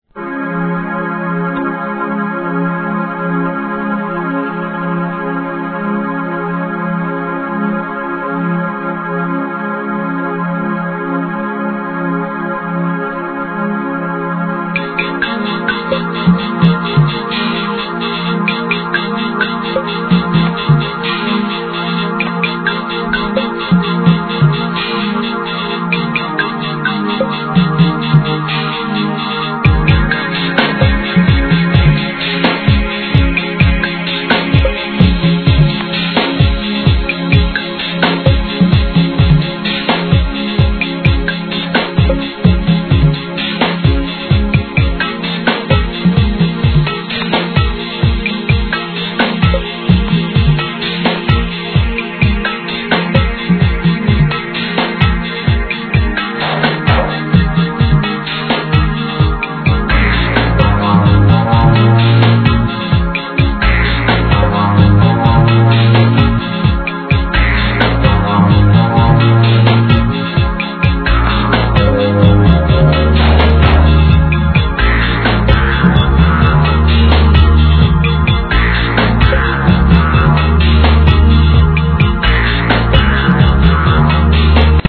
HIP HOP/R&B
抽象絵画のようなサウンドが脳内を刺激するブレイク物!!